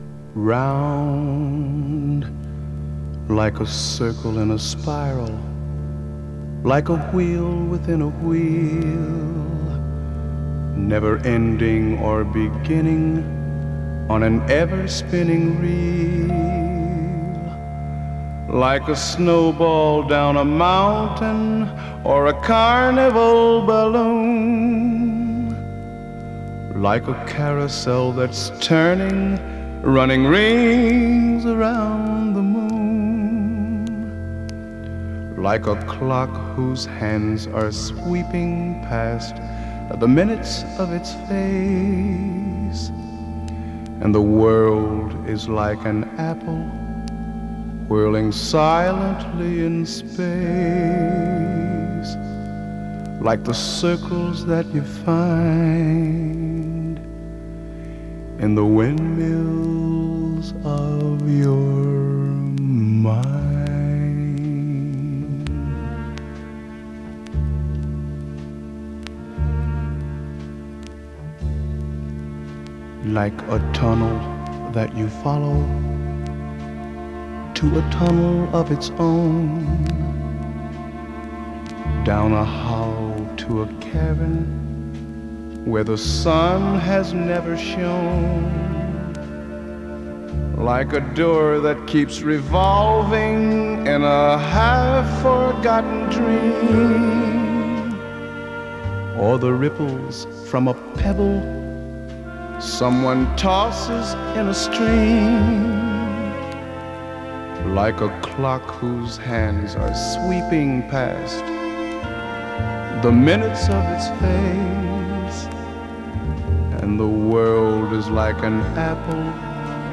＊音の薄い部分で時おり軽いチリ/パチ・ノイズ。